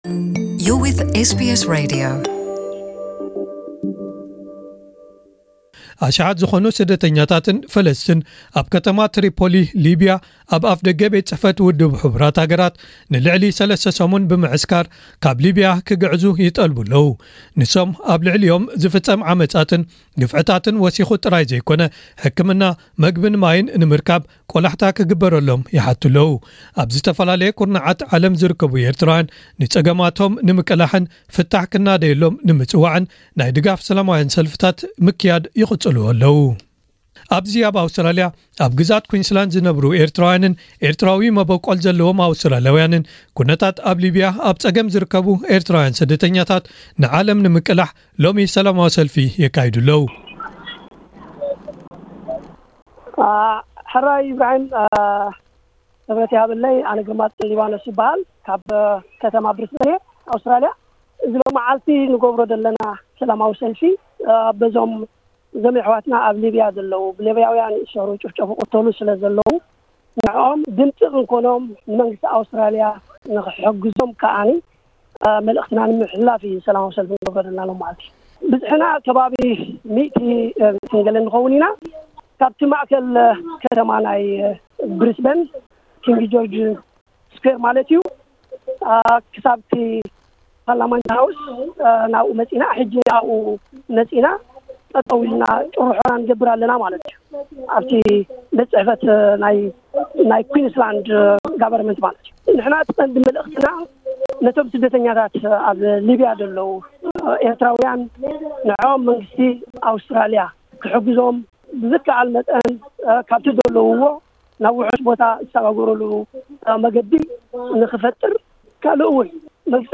ኣብ ከተማ ብሪዝበን ንምቅላሕ ጸገማት ኤርትራውያን ስደተኛታት ኣብ ሊብያ ሰላማዊ ሰልፊ ተኻይዱ (ጸብጻብ)